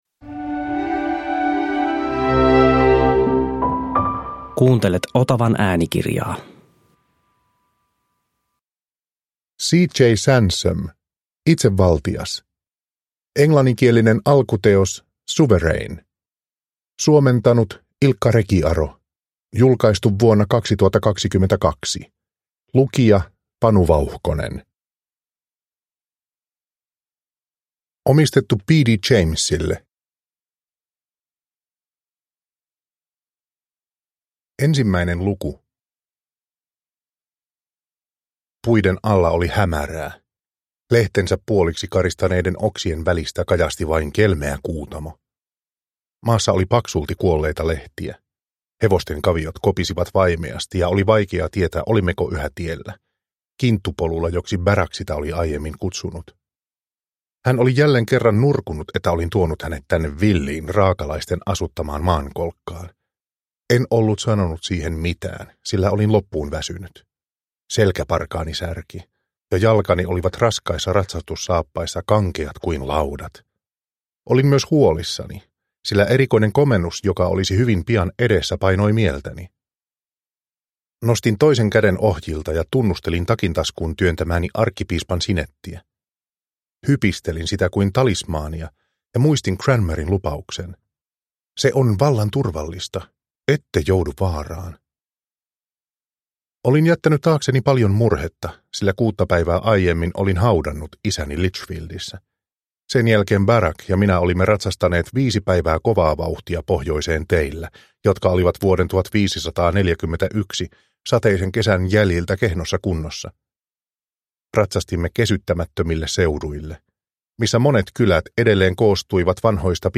Itsevaltias – Ljudbok – Laddas ner